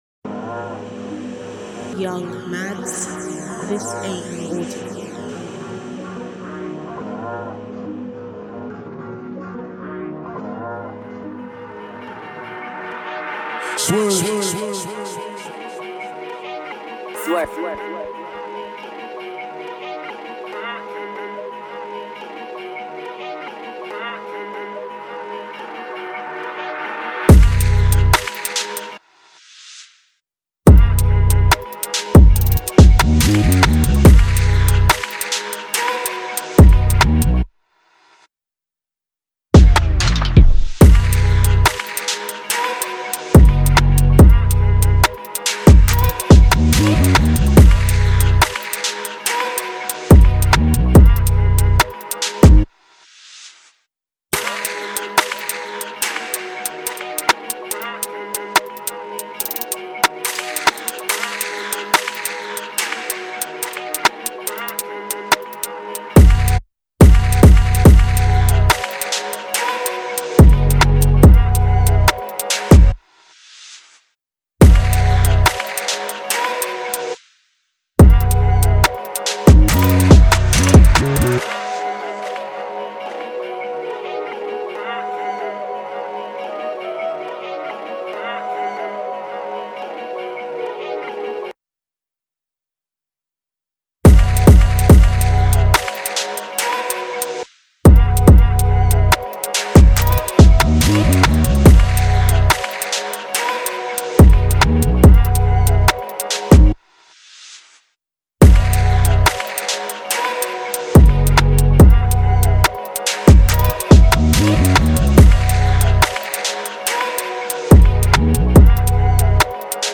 official instrumental
NY Drill Instrumentals